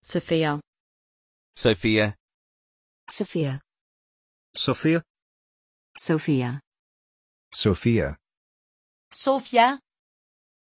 Prononciation de Sophia
pronunciation-sophia-en.mp3